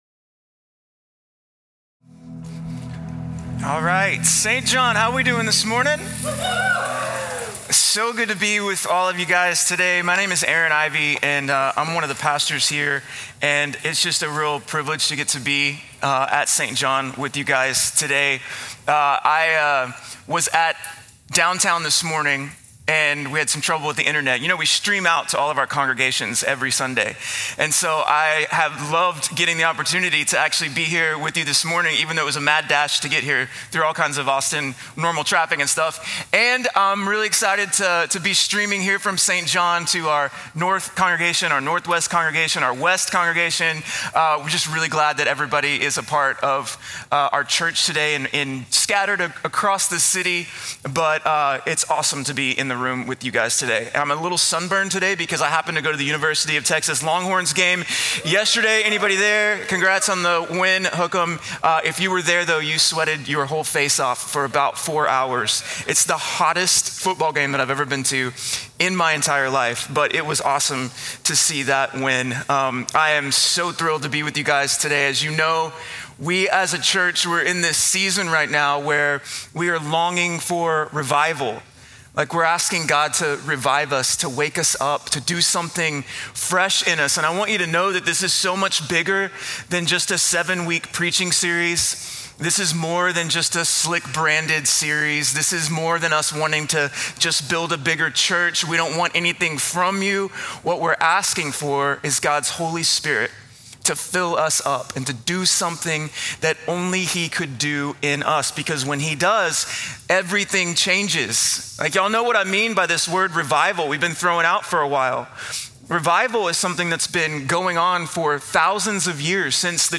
Download - Joy (Advent Spoken Word) | Podbean